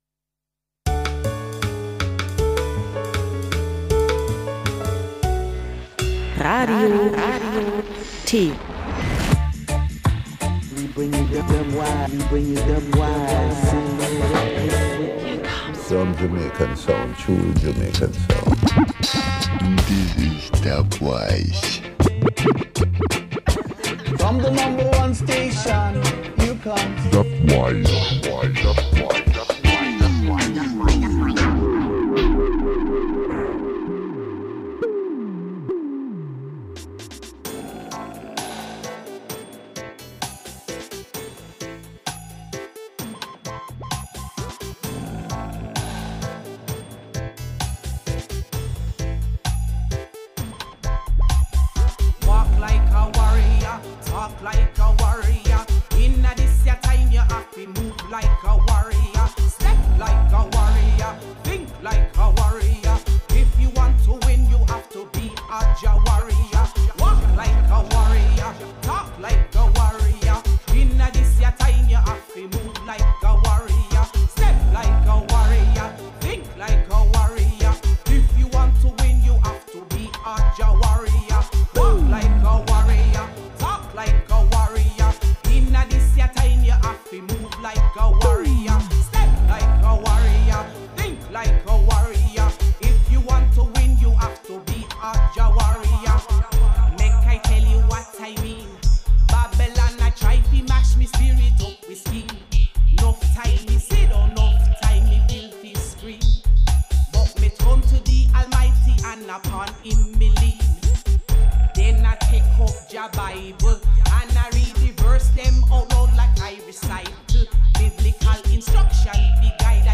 Forthcoming and fresh tunes included!